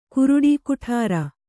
♪ kuruḍi kuṭhāra